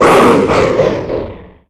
Catégorie:Cri Pokémon (Soleil et Lune) Catégorie:Cri de Draïeul